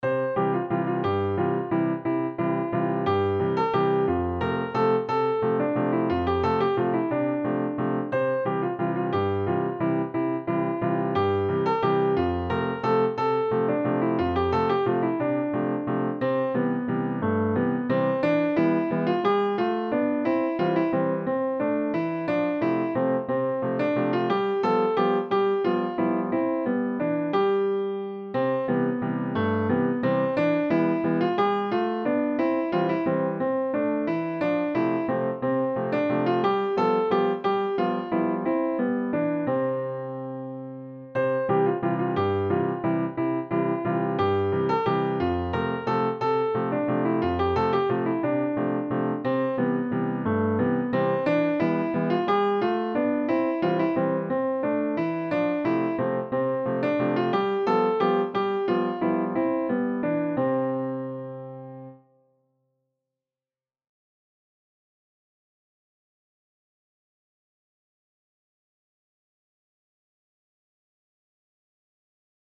A waltz in 31-EDO